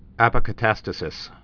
(ăpə-kə-tăstə-sĭs)